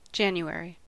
This comes about by analogy with "January" (/ˈæn.ju-/
En-us-January.ogg.mp3